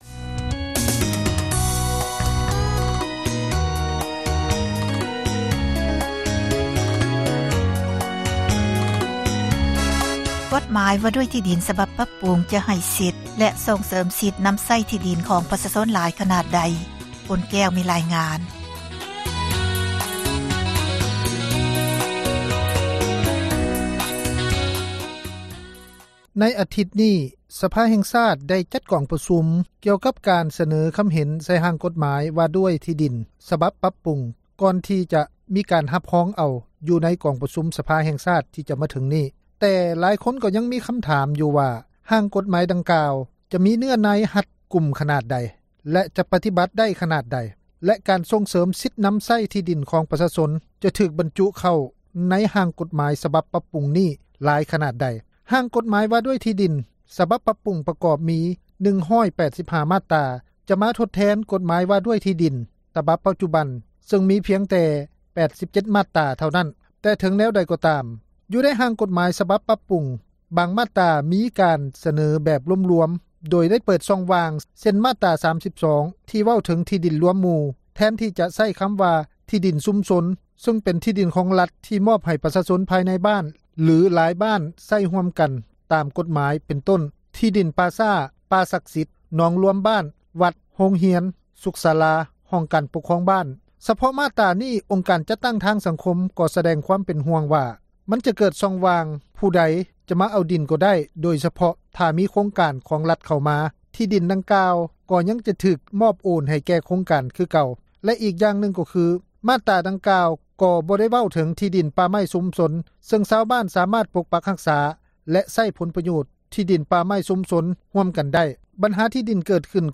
ການເອົາທີ່ດິນຂອງປະຊາຊົນໃຫ້ນັກລົງທຶນສຳປະທານ ເຮັດໃຫ້ປະຊາຊົນອັປໂຍດ, ດັ່ງ ທ່ານ ທອງລຸນ ສີສຸລິດ ນາຍົກຣັຖມົນຕຼີ ໄດ້ກ່າວຕື່ມອີກວ່າ: